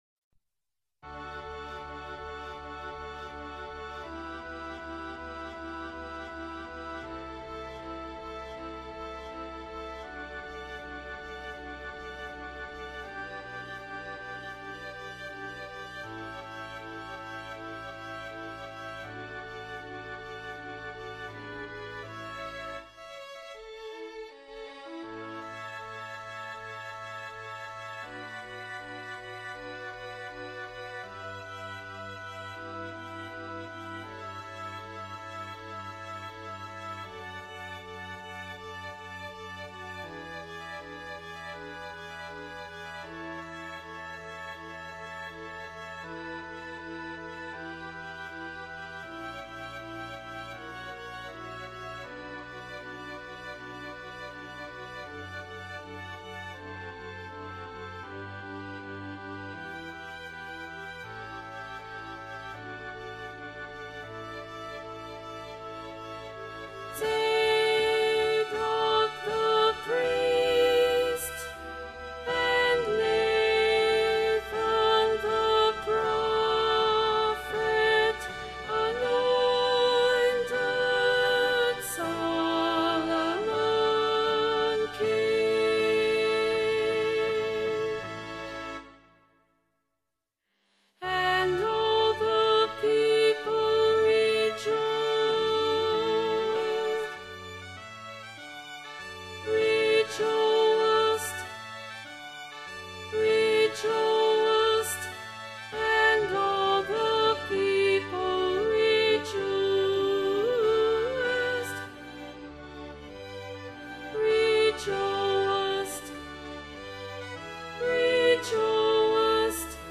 Altos
Emphasised voice and other voices